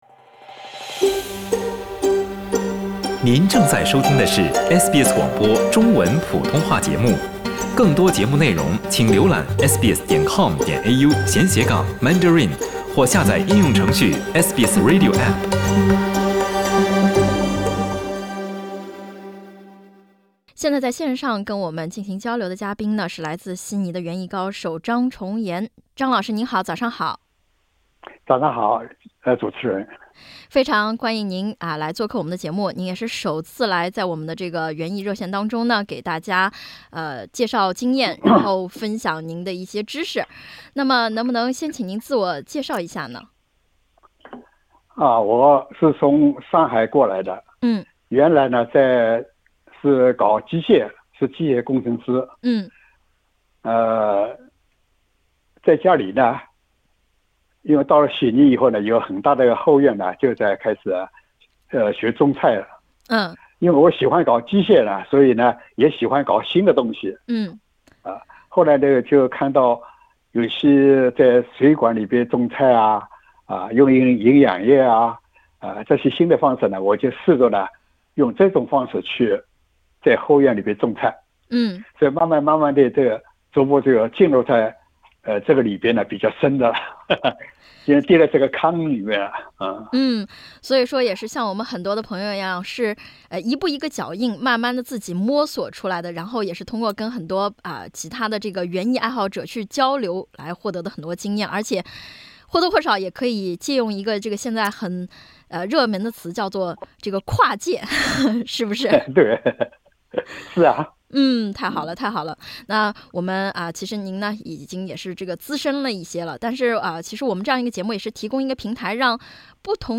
歡迎點擊封面圖片收聽完整寀訪。